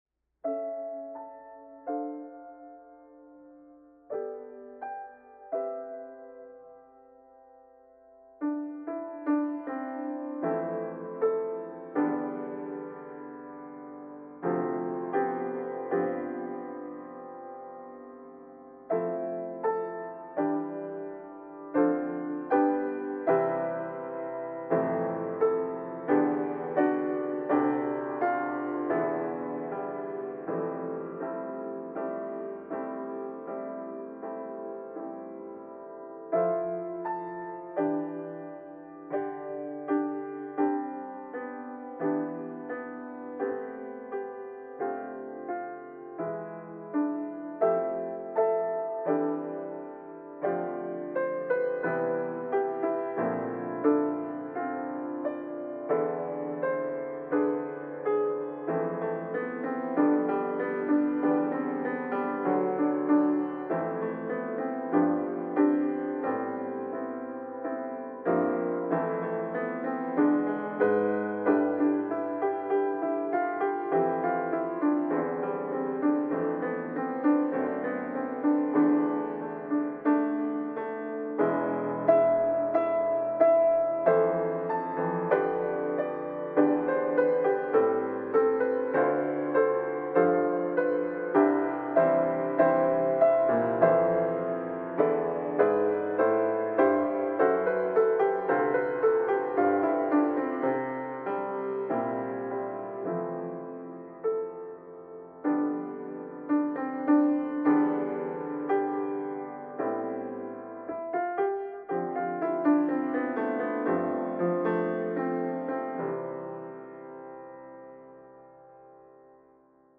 自然の中で安らいだ気持ちや 風が吹いて時が流れたことを 穏やかな運びで何度も聞けるように ピアノ曲を１０曲描きました。